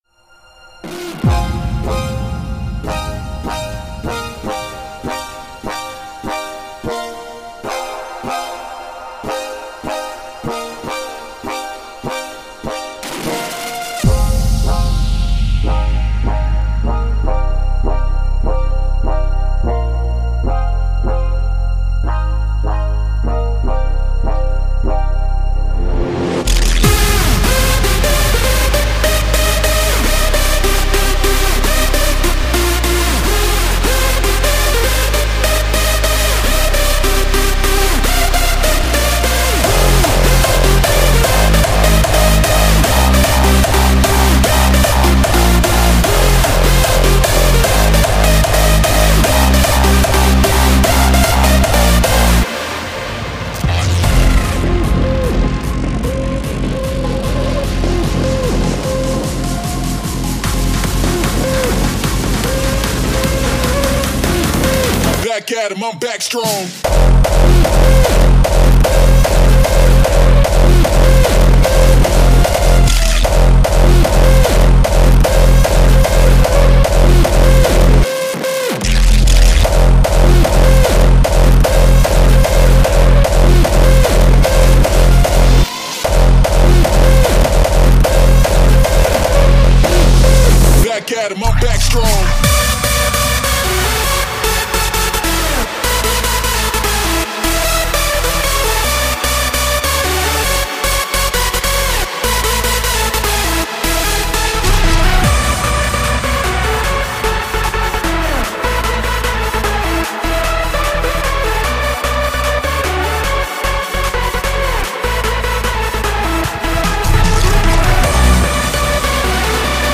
6天前 Hardstyle 39 推广
Hardstyle 在鼓组制作方面是一个复杂的音乐类型